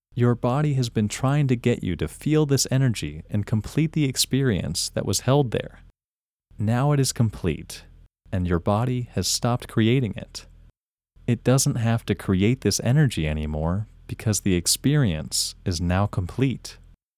IN – First Way – English Male 29
IN-1-English-Male-29.mp3